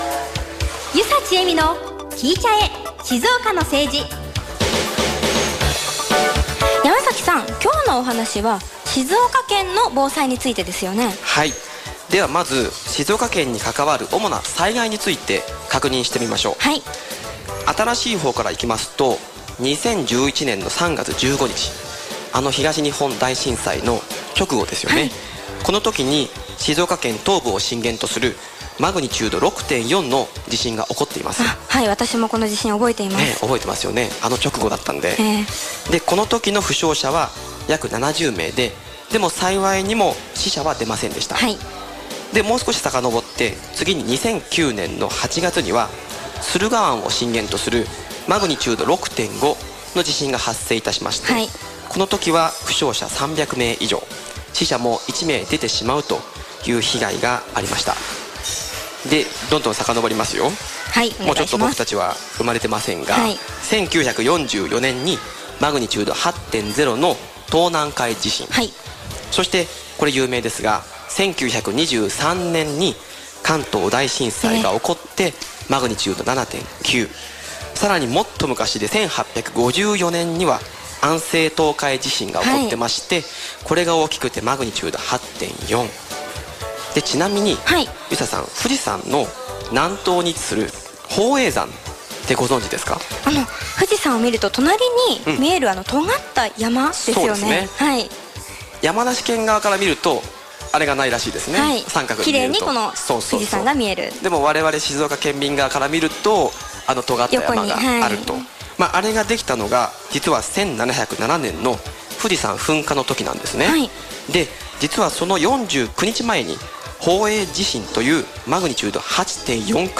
第２回ラジオ生放送「静岡県の防災」